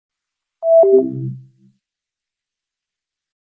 Quest Shutdown Efeito Sonoro: Soundboard Botão